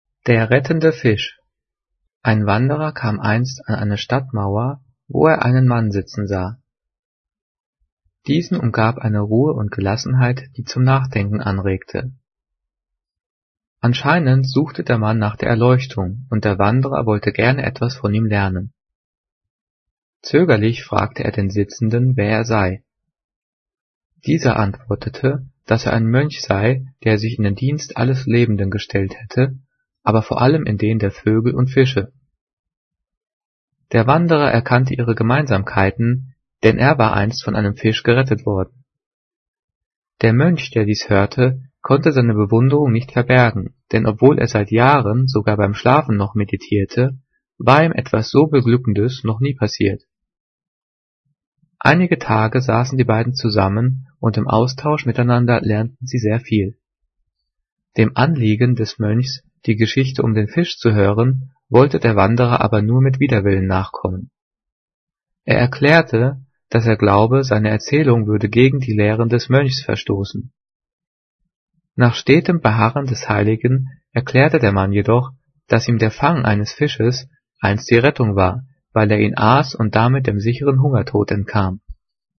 Gelesen:
gelesen-der-rettende-fisch.mp3